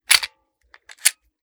9mm Micro Pistol - Cocking Slide 001.wav